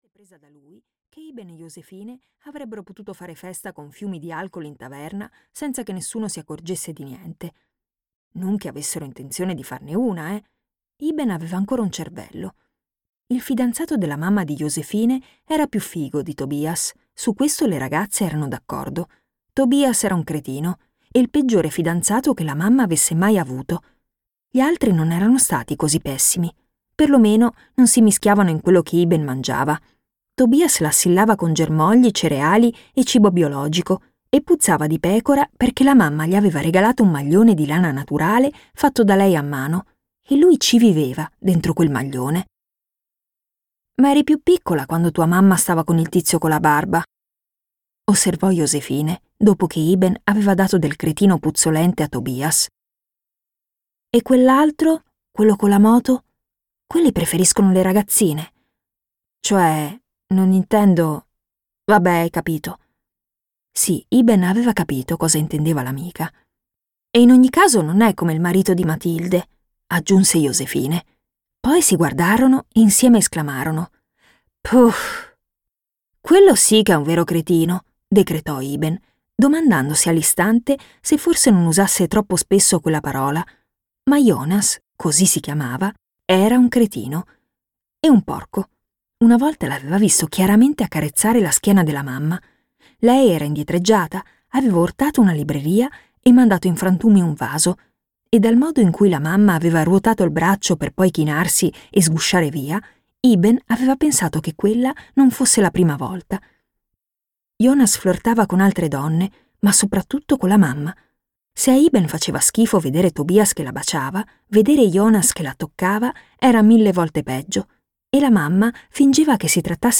"Piccole dosi di felicità" di Siri Ostli - Audiolibro digitale - AUDIOLIBRI LIQUIDI - Il Libraio